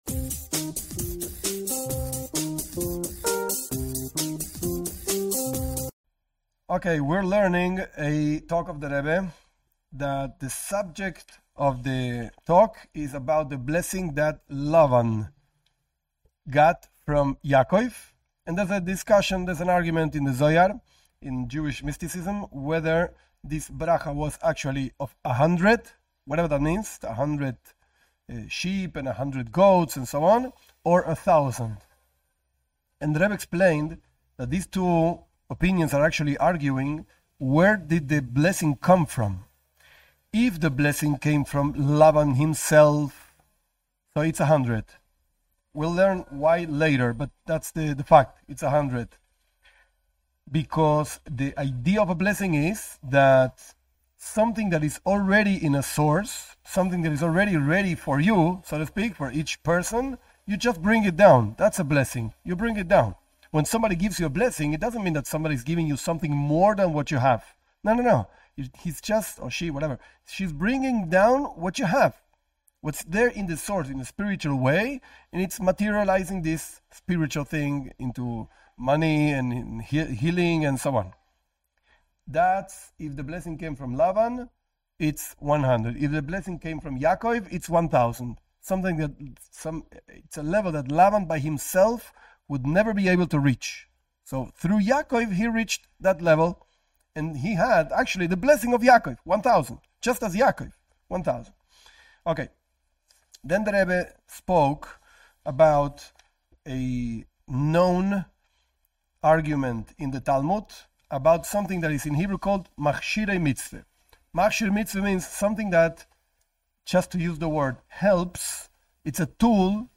This class is parts of a talk by the Rebbe on the blessings that Lavan received because of Yaakov. In explaining two views of the Zohar, the Rebbe touches upon fundamental concepts for non Jews.Based on Likutei Sichot, vol. 20, p. 136